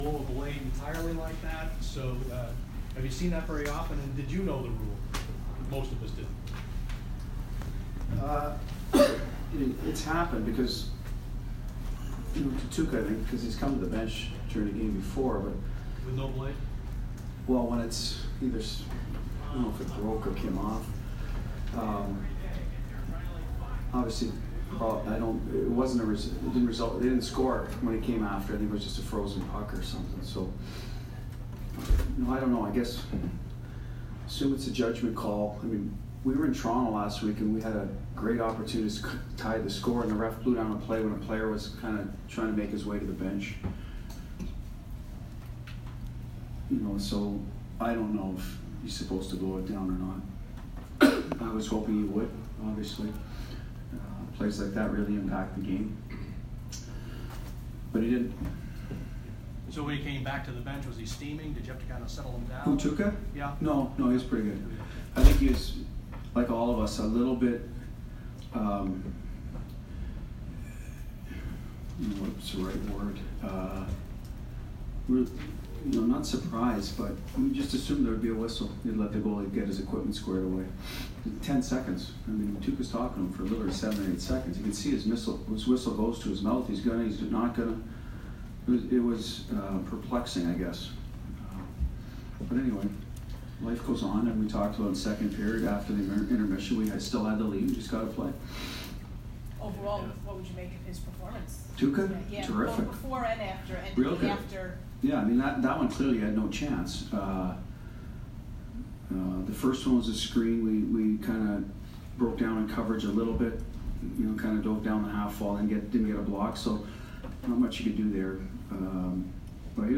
Bruce Cassidy post-game 4/28